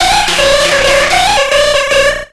Cri de Lippoutou dans Pokémon X et Y.